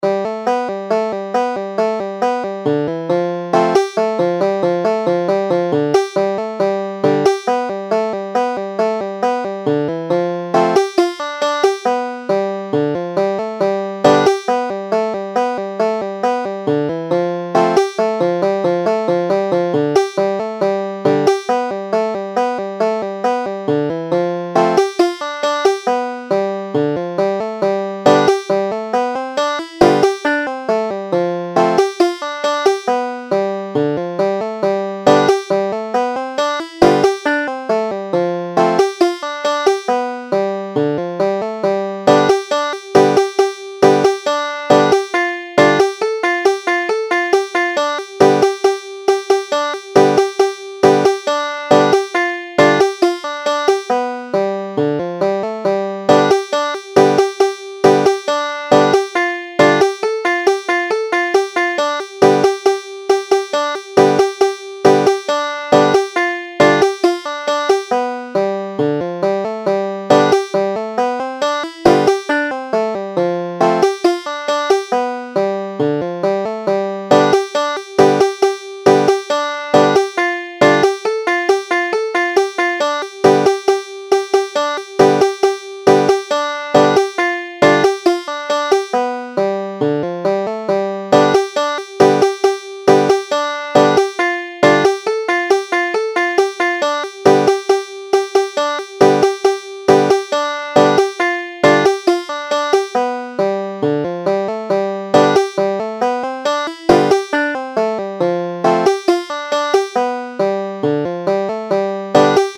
Banjo
Jenny on the Railroad Standard G A   tab | audio tab |